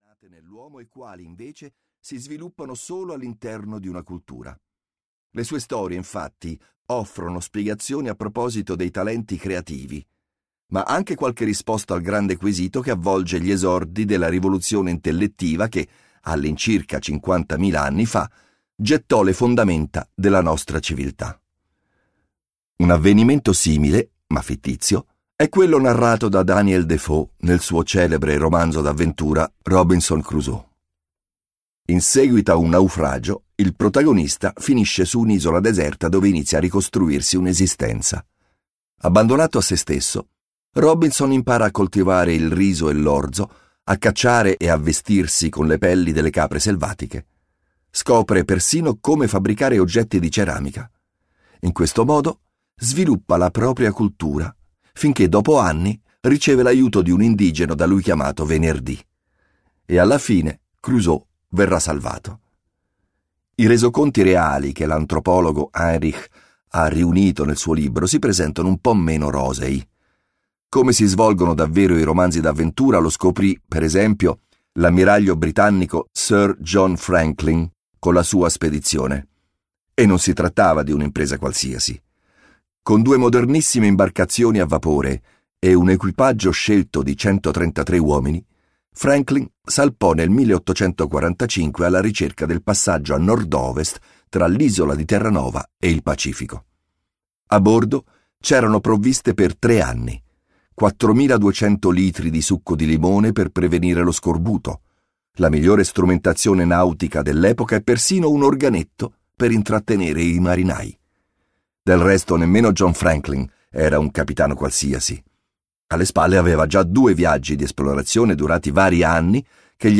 "Come cambiamo il mondo" di Stefan Klein - Audiolibro digitale - AUDIOLIBRI LIQUIDI - Il Libraio